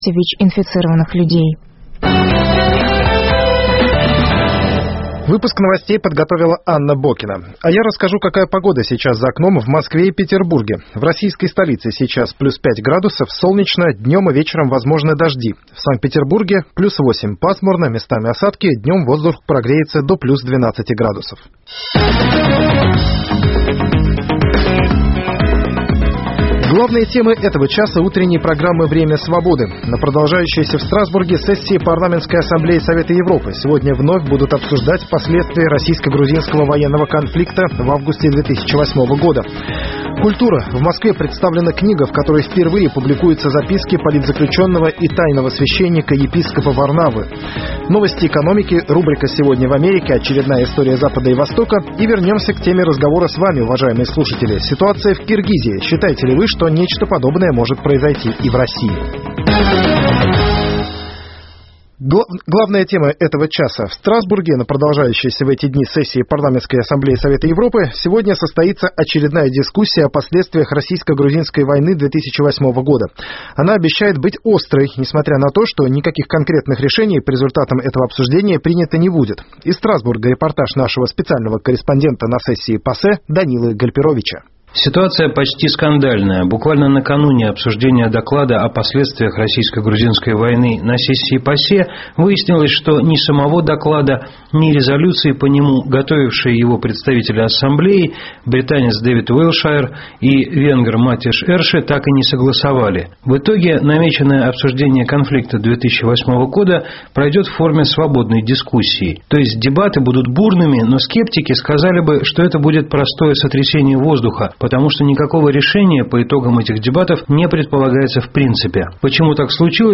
С 9 до 10 часов утра мы расскажем о том, что готовит нам начинающийся день. Представим панораму политических, спортивных, научных новостей, в прямом эфире обсудим с гостями и экспертами самые свежие темы нового дня, поговорим о жизни двух российских столиц.